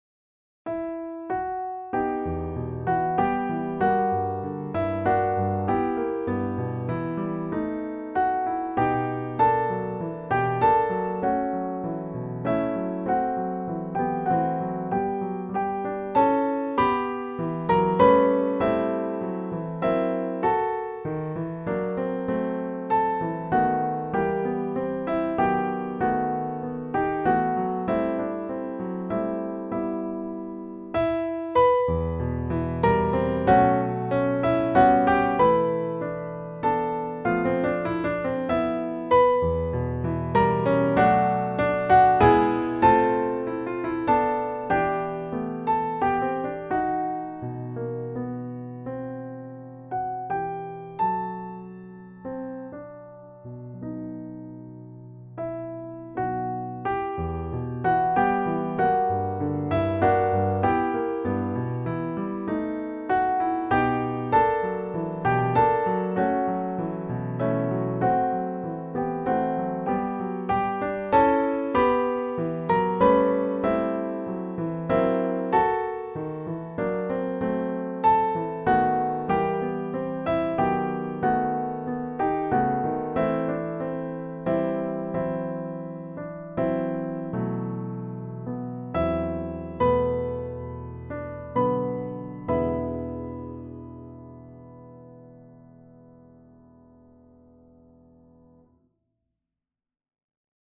Piano duet 1st part easy